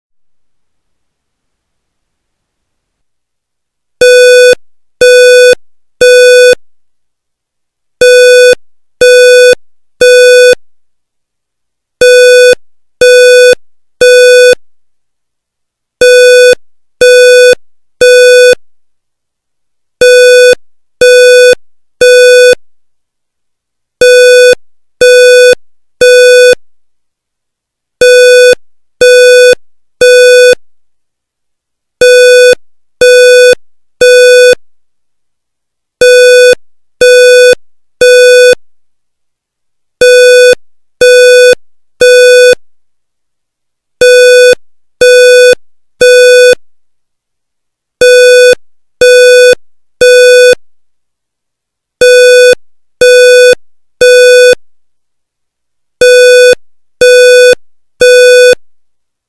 The fire alarm systems at the Fennell and Stoney Creek campuses are two-stage alarm systems.
Second Stage
Three fast beeps
2ndStageAlarmTone.wma